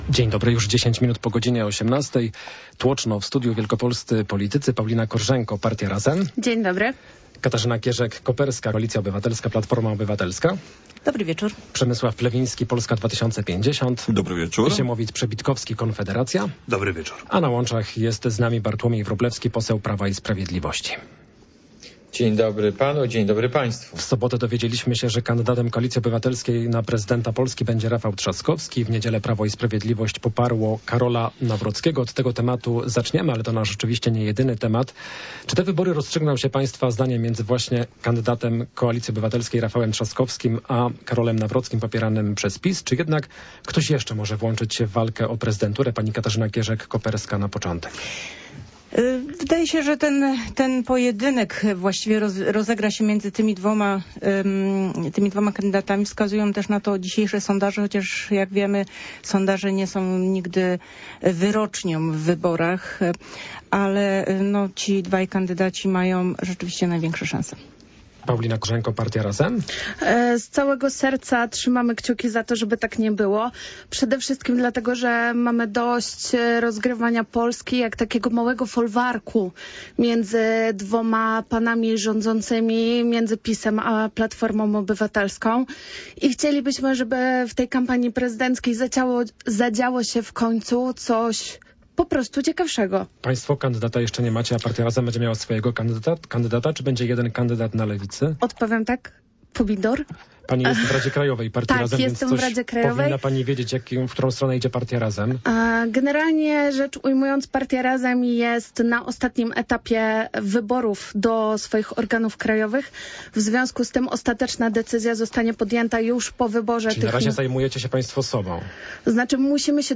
O kandydatach na prezydenta Polski, wolnej wigilii, składce zdrowotnej i CPK dyskutują goście Rozmowy bardzo politycznej w Radiu Poznań.